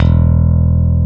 Index of /90_sSampleCDs/Roland - Rhythm Section/BS _E.Bass 2/BS _Rock Bass
BS  ROCKBSE2.wav